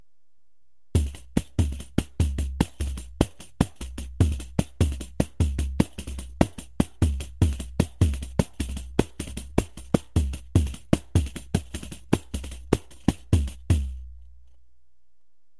To sound right the pandeiro has to be tuned very lov.
This is a double-time-jungle-thingy that goes well with certain spacy slow tempo music. The tempo has to be slow because of the fast double-time beats.
In the soundfile I play 2x basic + 2x the last variation.